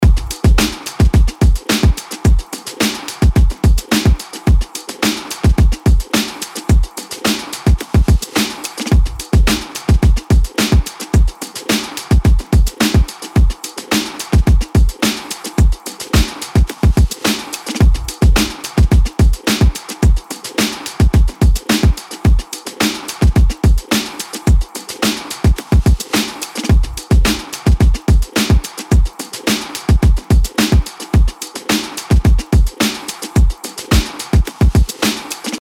LP 214 – DRUM LOOP – OLD SCHOOL – 108BPM